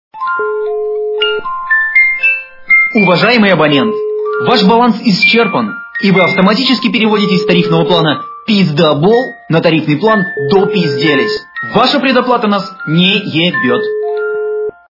При прослушивании Говорящий телефон - Уважаемый абонент, вы переводитесь на другой тарифный план... качество понижено и присутствуют гудки.